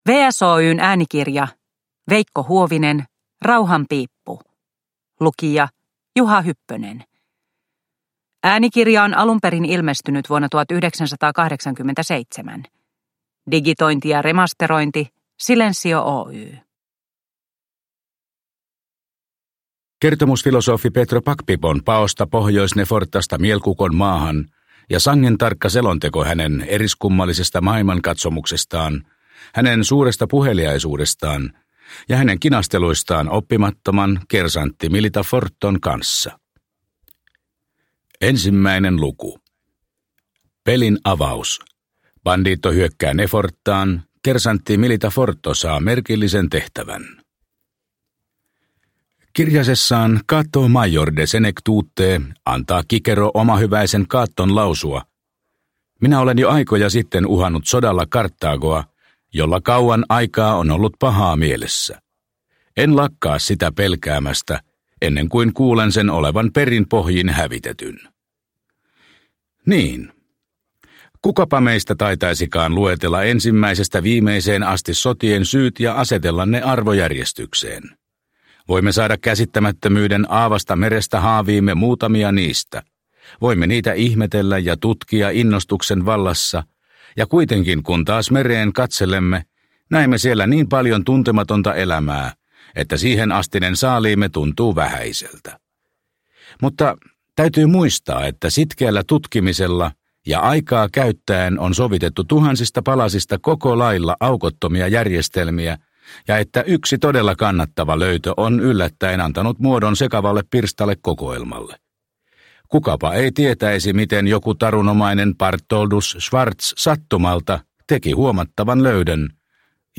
Rauhanpiippu – Ljudbok